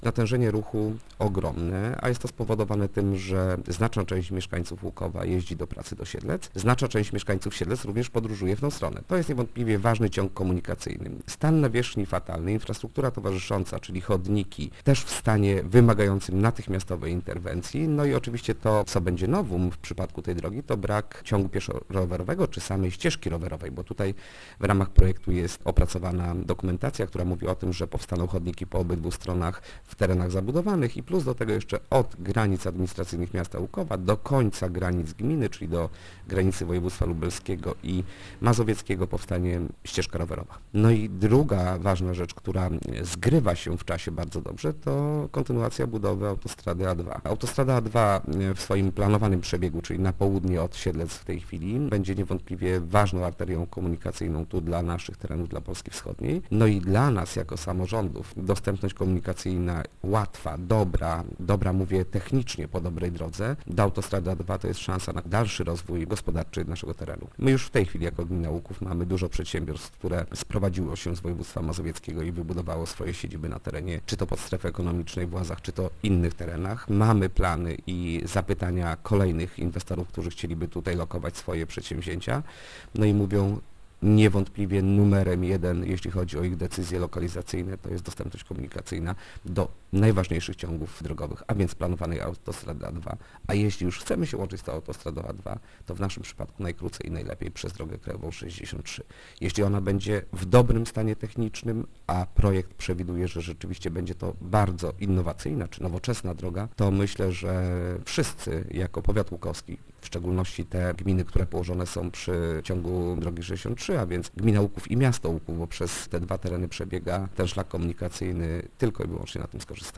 Wójt gminy Łuków Mariusz Osiak nie ma wątpliwości, że przebudowa wspomnianej trasy jest niezbędna. - To droga niezwykle ważna dla rozwoju nie tylko naszej gminy, a jej stan jest po prostu katastrofalny - mówi Informacyjnej Agencji Samorządowej wójt Osiak: